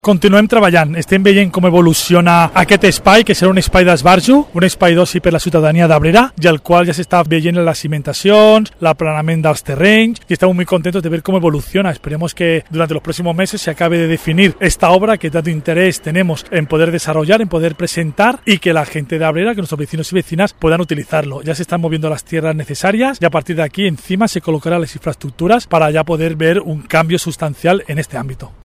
VISITA PÍCNIC TORRENT GRAN 24-05-23 JESÚS NAHARRO, ALCALDE.mp3